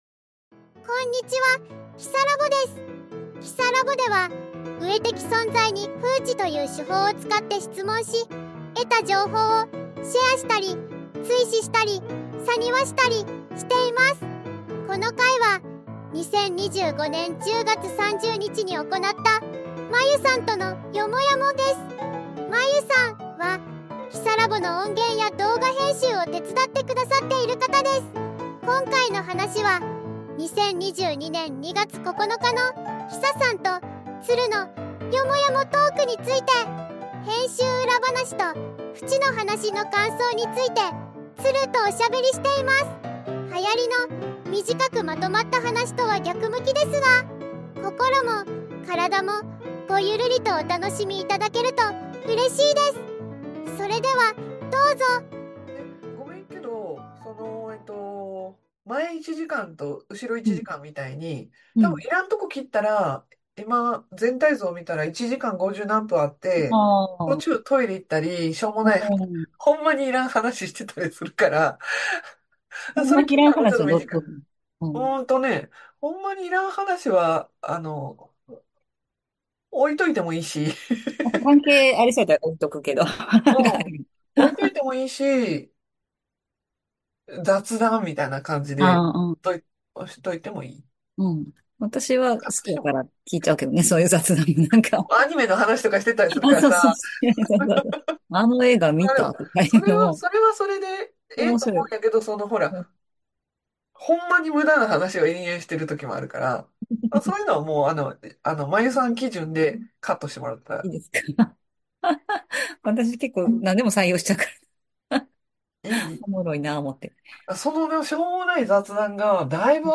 「よもやも話」